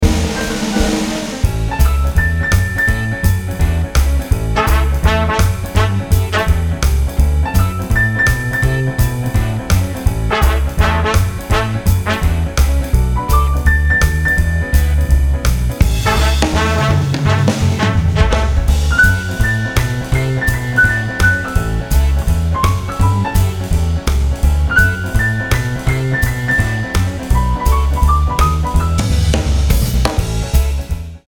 167 BPM